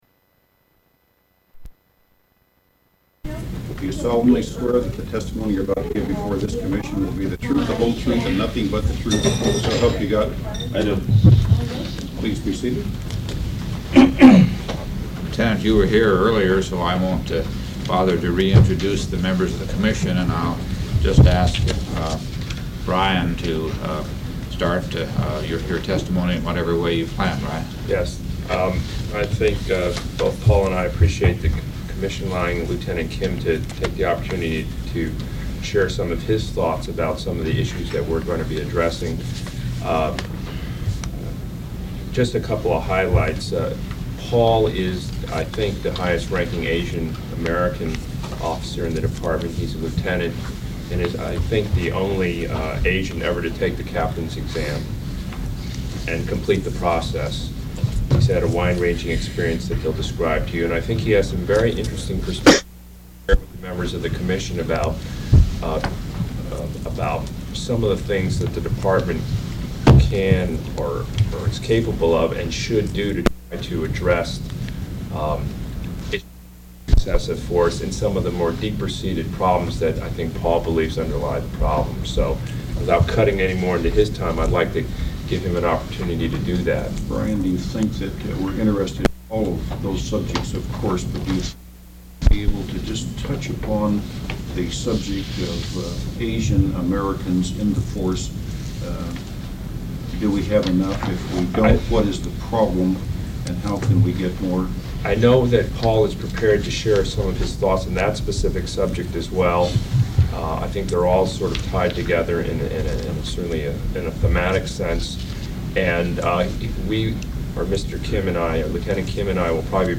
Executive session.